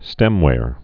(stĕmwâr)